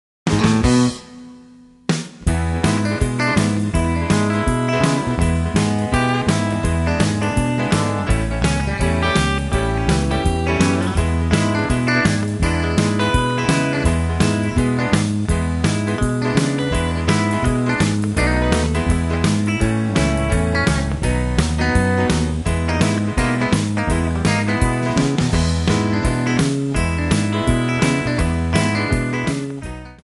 MPEG 1 Layer 3 (Stereo)
Backing track Karaoke
Rock, Oldies, 1950s